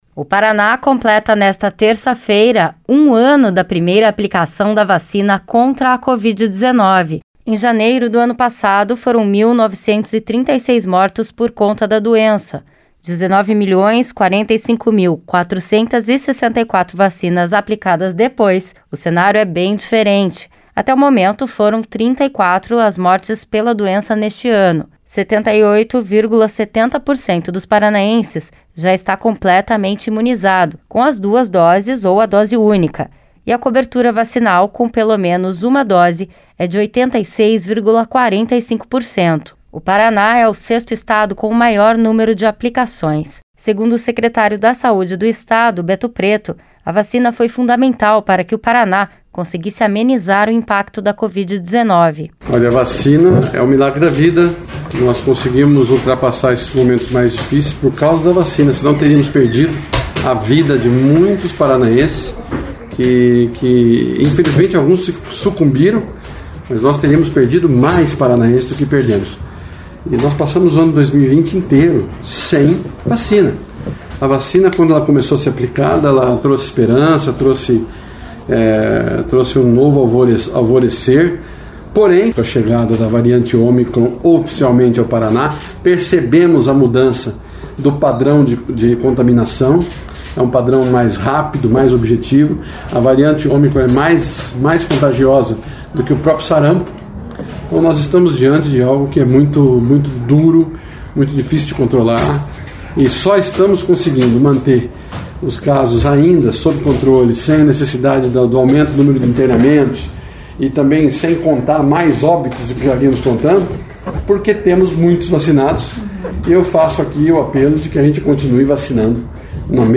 Na reportagem entenda o impacto que ela teve no controle da pandemia.